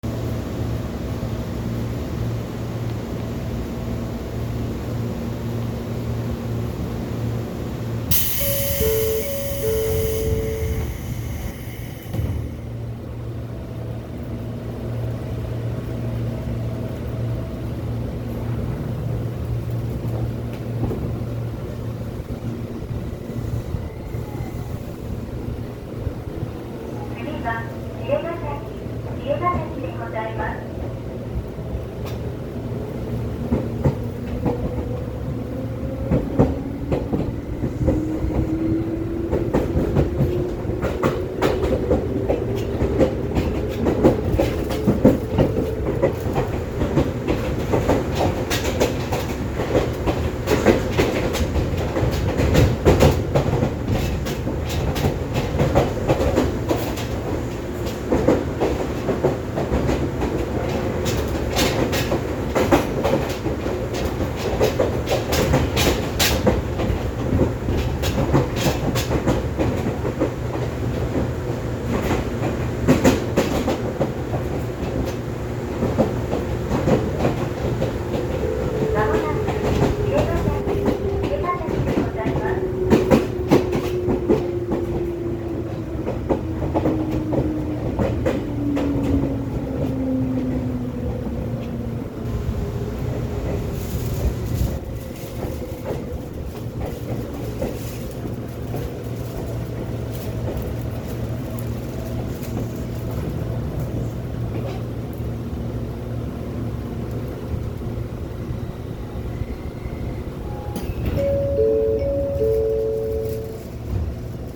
〜車両の音〜
・5000形走行音
【流山線】馬橋→幸谷
もと西武101系なので、国鉄103系に類似した音になっています。